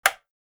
دانلود صدای موس 30 از ساعد نیوز با لینک مستقیم و کیفیت بالا
جلوه های صوتی